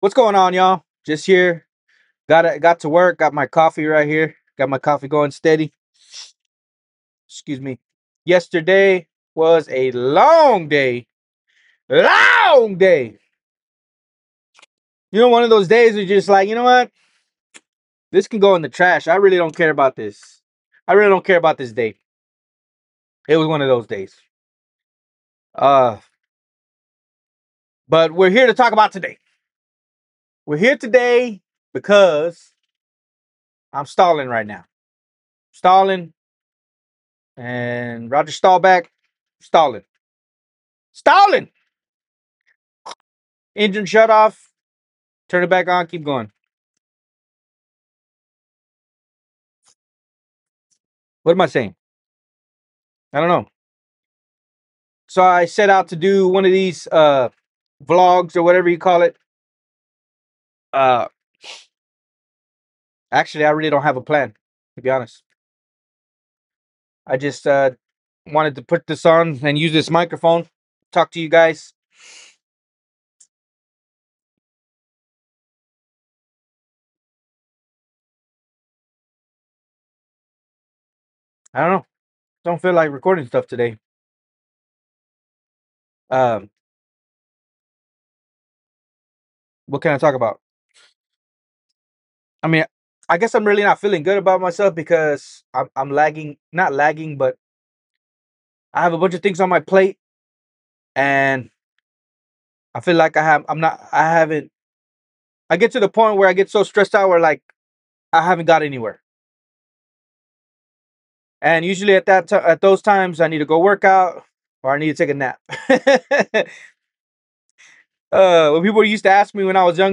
Random thoughts vlog after a long, rough day.